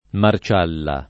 [ mar ©# lla ]